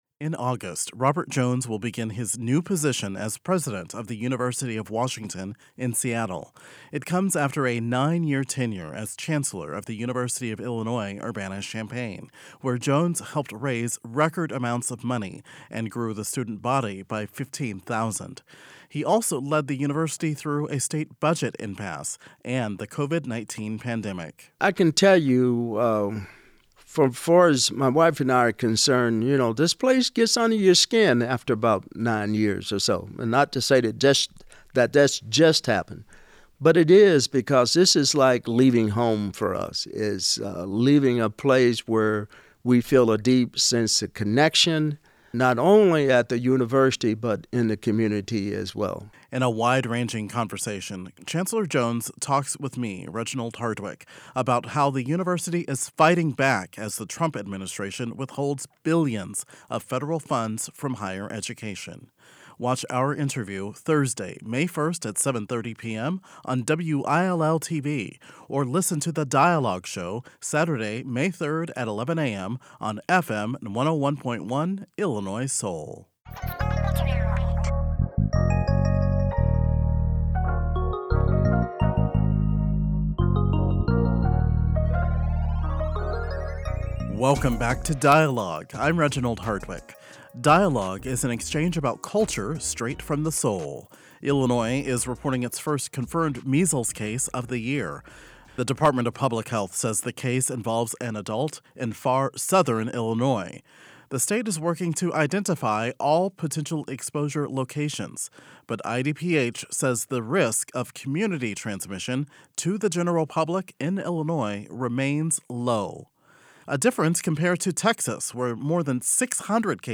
In this segment of Dialogue, an official from the state health department joined the conversation to discuss common symptoms and outline Illinois’ response plans for a measles outbreak.